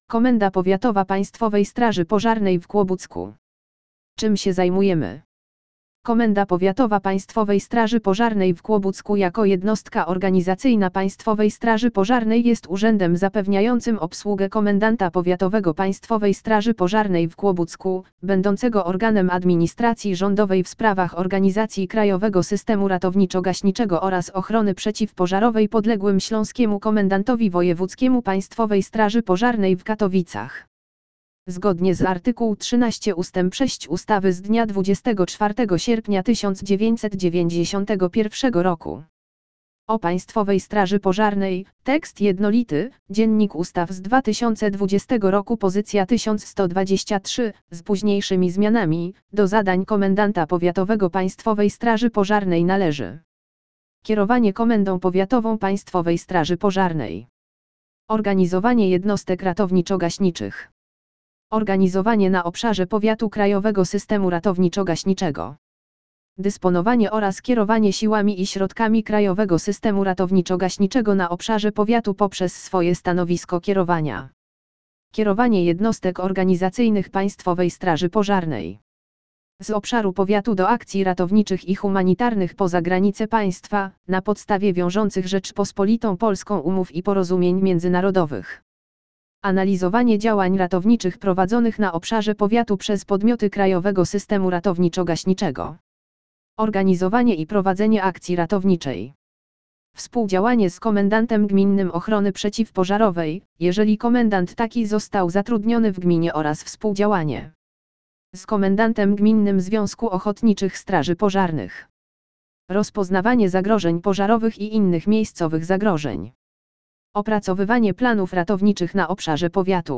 Informacje o Komendzie Powiatowej Państwowej Straż Pożarnej w Kłobucku w tekście odczytywanym maszynowo
KP PSP Kłobuck - tekst odczytany maszynowo - MP3